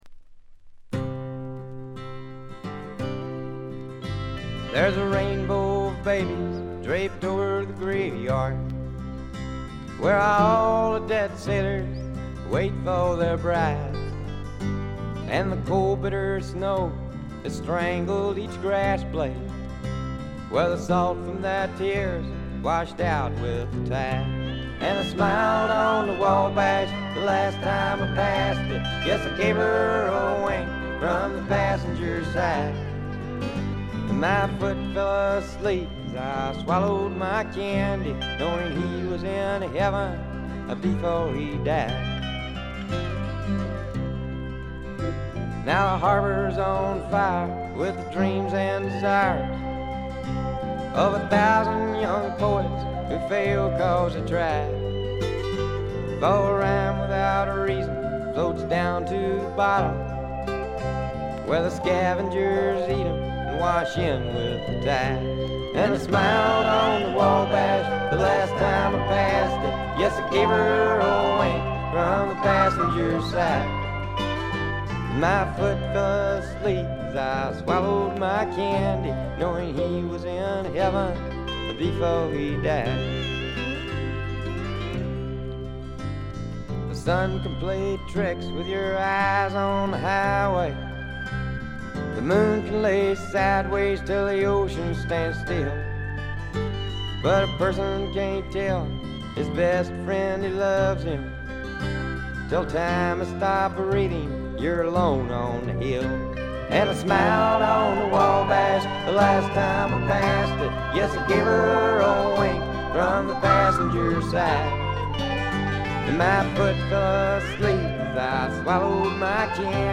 部分試聴ですが軽微なチリプチ少々程度。
試聴曲は現品からの取り込み音源です。
vocals, acoustic guitar